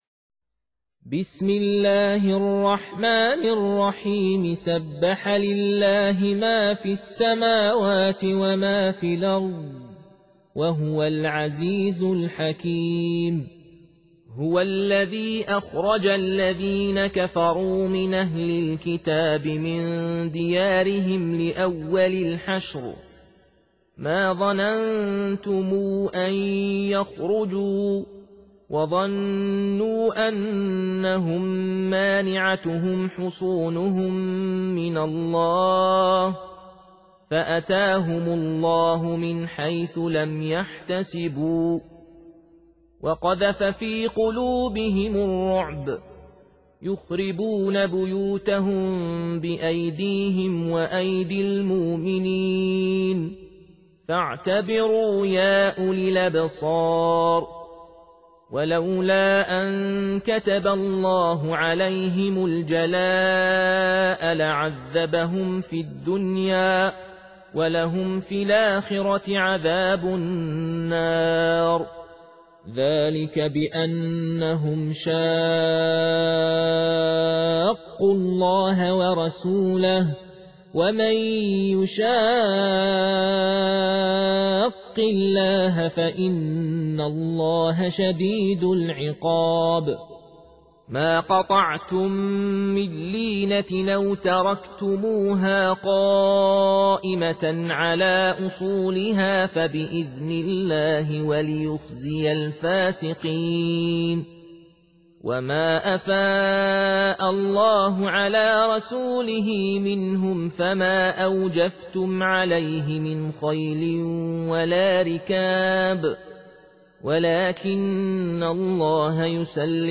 Surah Sequence تتابع السورة Download Surah حمّل السورة Reciting Murattalah Audio for 59. Surah Al-Hashr سورة الحشر N.B *Surah Includes Al-Basmalah Reciters Sequents تتابع التلاوات Reciters Repeats تكرار التلاوات